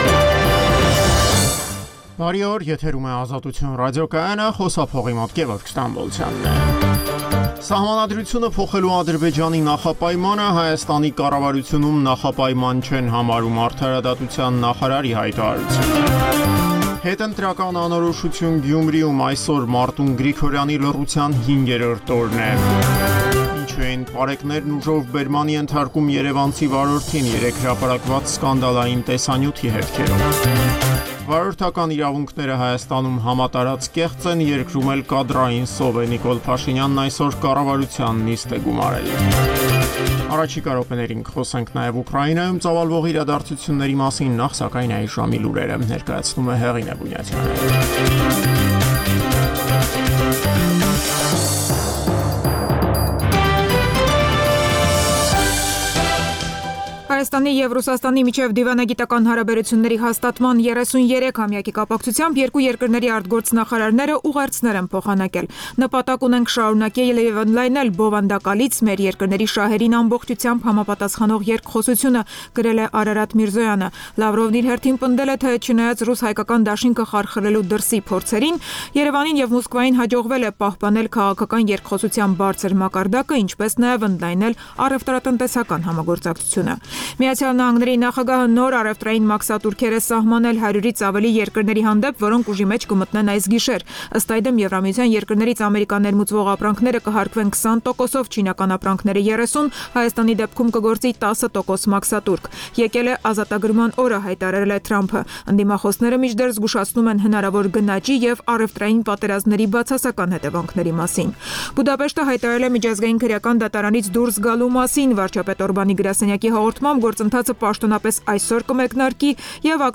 Տեղական եւ միջազգային լուրեր, ռեպորտաժներ զարգացող իրադարձությունների մասին, այդ թվում՝ ուղիղ եթերում, հարցազրույցներ, տեղական եւ միջազգային մամուլի տեսություն: